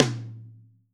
TOM 2H    -L.wav